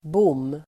Uttal: [bom:]